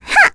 Requina-Vox_Attack3.wav